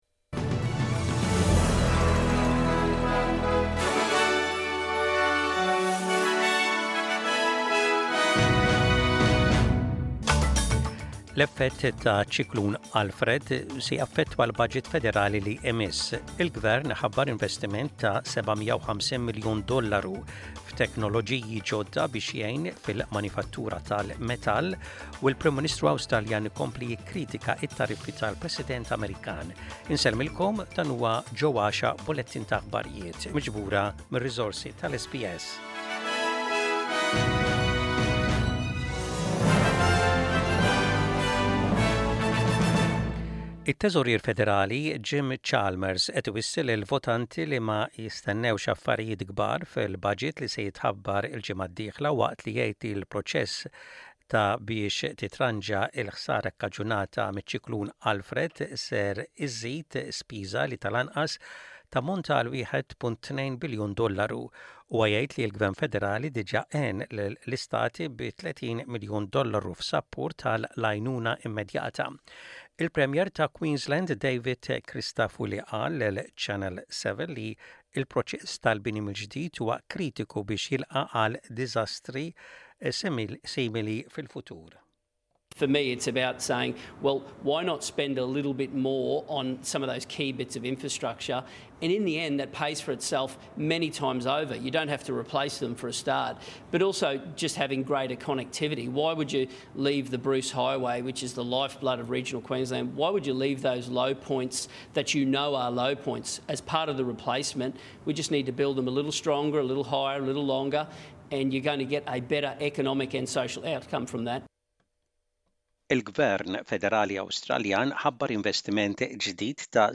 Aħbarijiet bil-Malti: 18.03.25